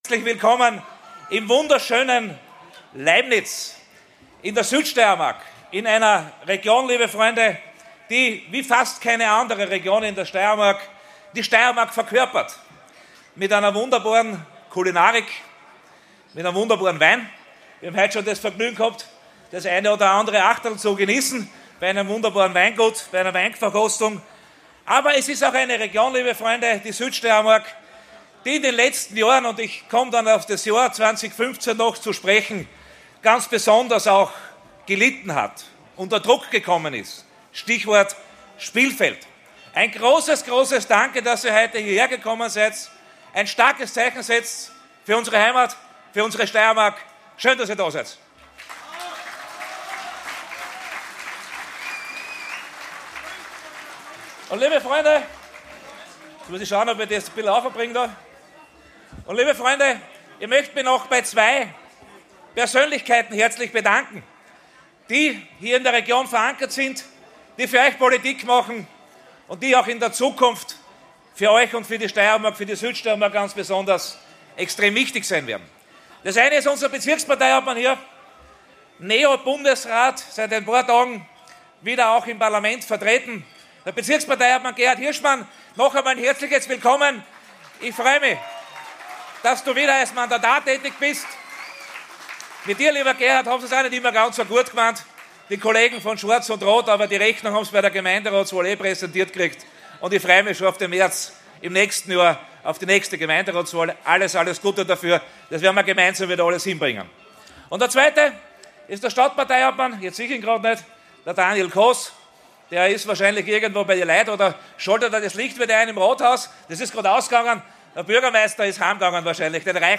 Die Stimme des Steiermark-Kandidaten: Mario Kunasek spricht vor 1.400 Besuchern in Leibnitz ~ Steirisch g'redt - Der Podcast für Steirer! Podcast
Beschreibung vor 1 Jahr In dieser Folge hörst du die ungekürzte Rede von Mario Kunasek, dem FPÖ-Spitzenkandidaten für die Landtagswahl in der Steiermark. Vor einer Rekordanzahl an Besuchern hat Kunasek seine politischen Ziele und Visionen präsentiert. Erlebe die Stimmung hautnah und tauche ein in die Atmosphäre dieser gut besuchten Veranstaltung. Ohne Schnitte, direkt und unverfälscht – höre selbst, was Kunasek vor seinem begeisterten Publikum zu sagen hatte.